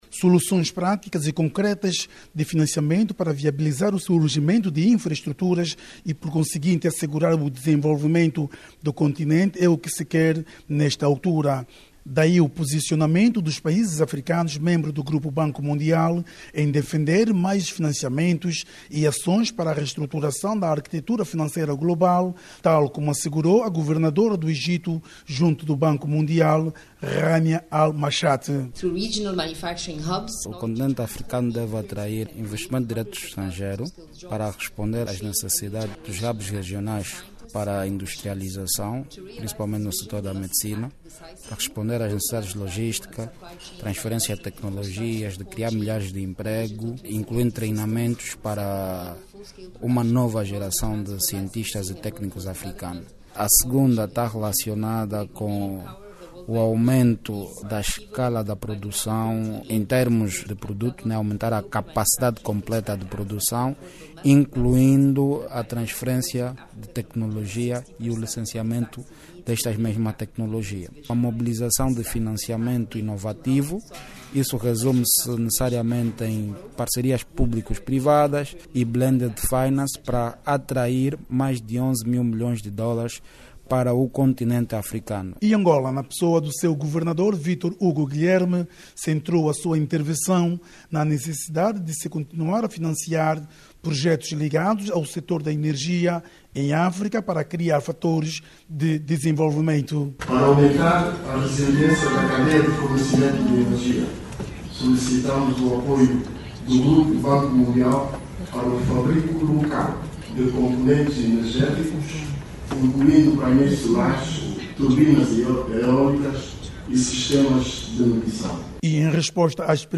Clique no áudio abaixo e saiba mais com o jornalista